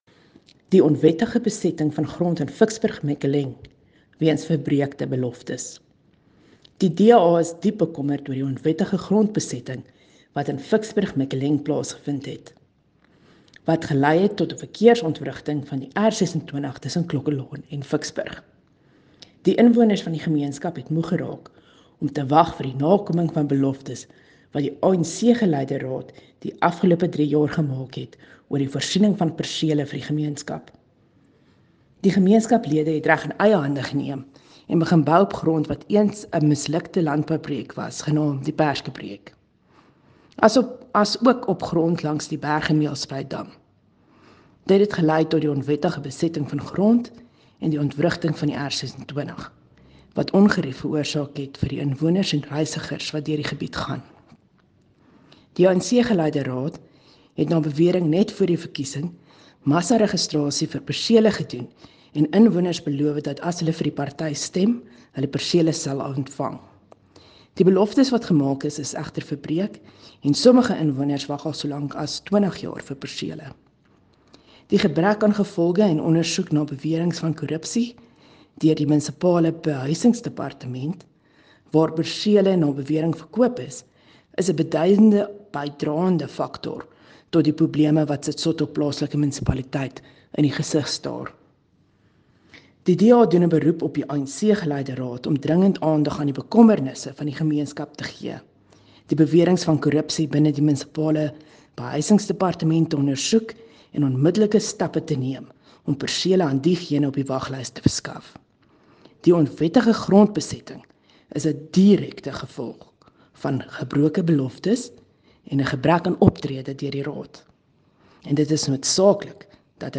Afrikaans soundbites by Cllr Riette Dell and